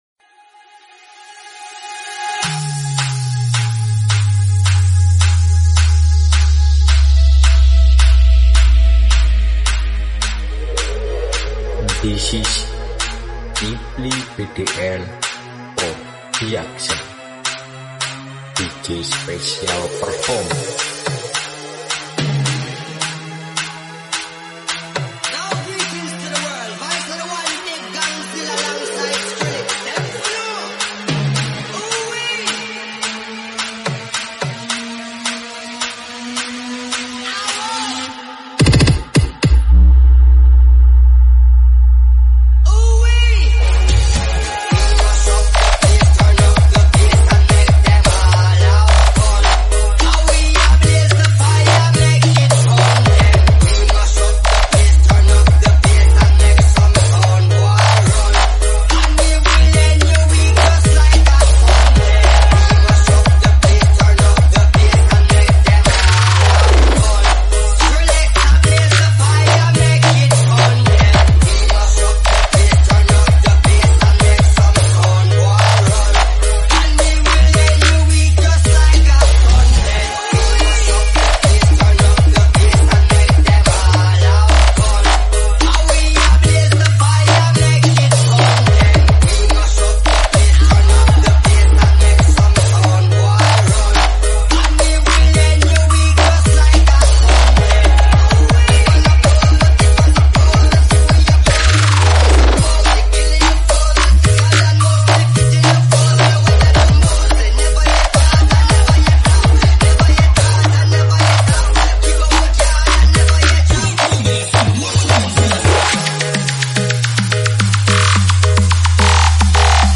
SOUNDS TREND FULLBASS SLOWLY